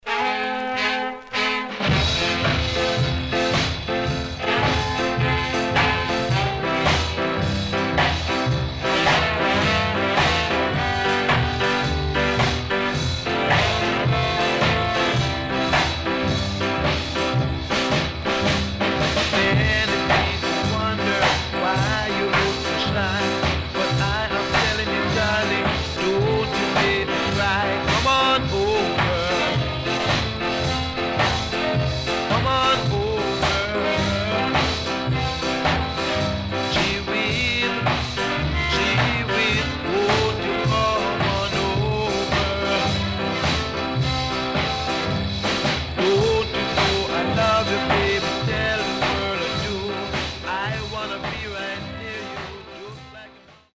CONDITION SIDE A:VG(OK)
W-Side Nice Early Ska
SIDE A:うすいこまかい傷ありますがノイズあまり目立ちません。